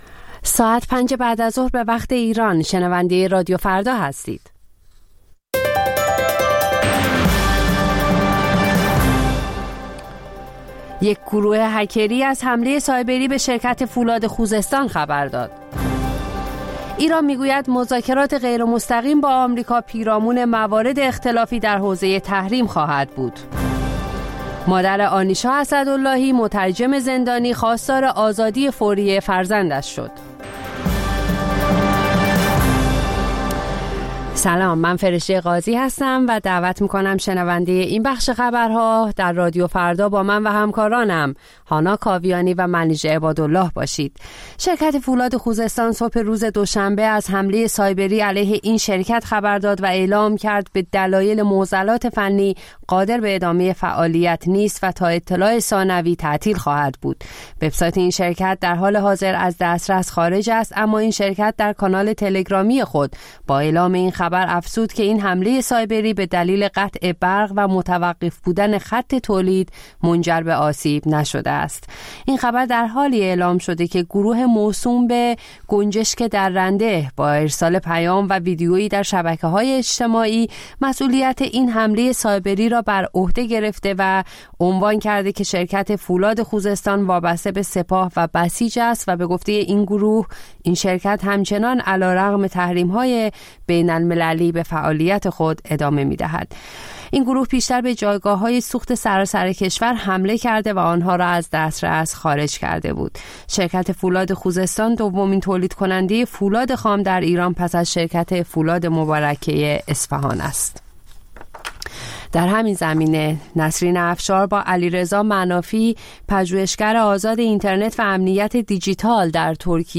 خبرها و گزارش‌ها ۱۷:۰۰